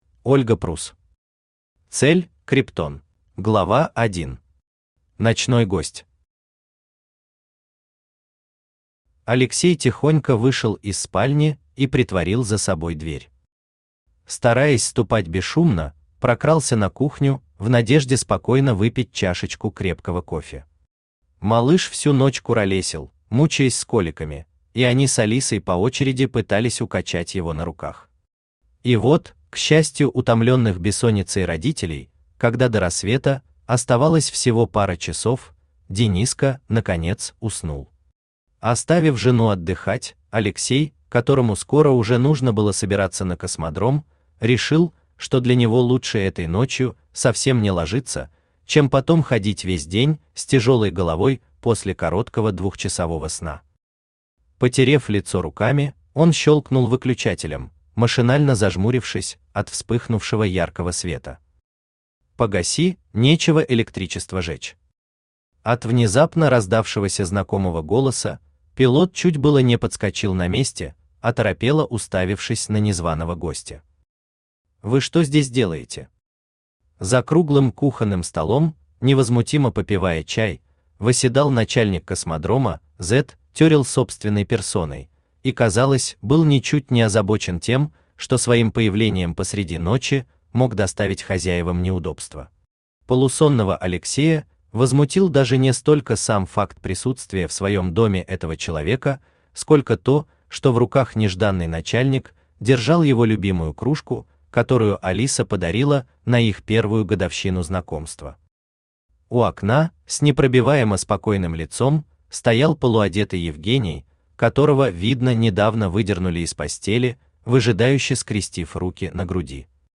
Аудиокнига Цель – Криптон | Библиотека аудиокниг
Aудиокнига Цель – Криптон Автор Ольга Прусс Читает аудиокнигу Авточтец ЛитРес.